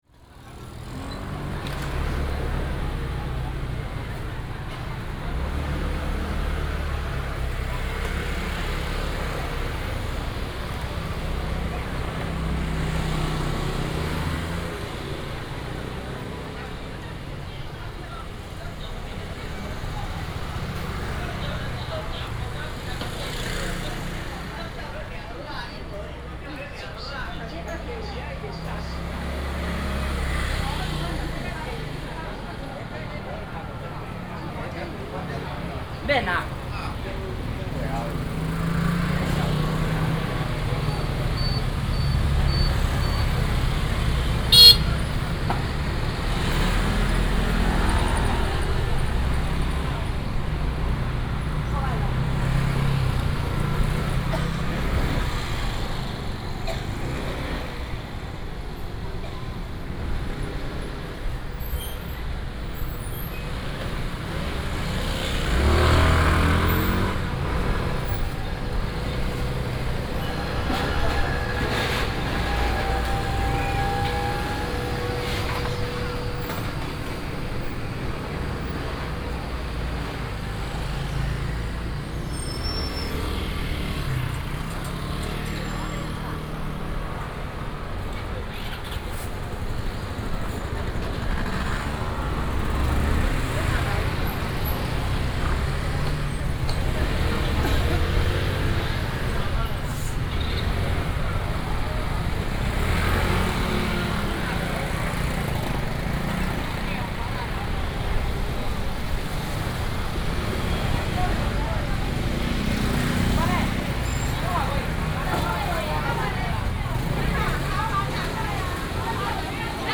Nantian Rd., East Dist., Chiayi City - Walking through the traditional market